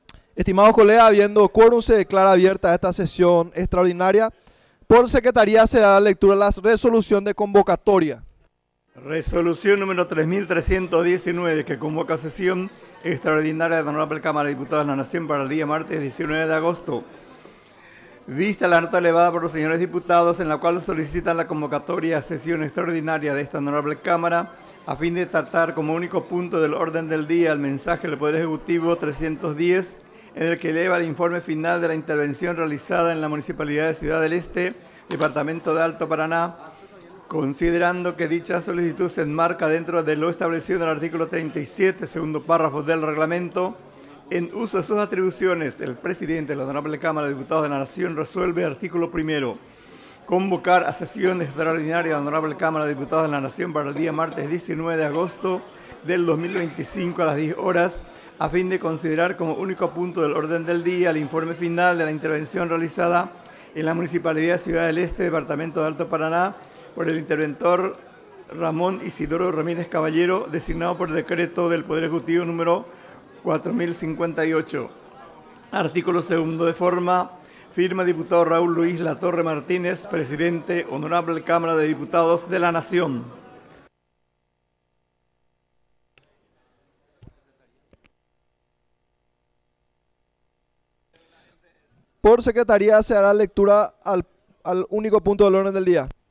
Sesión Extraordinaria, 19 de agosto de 2025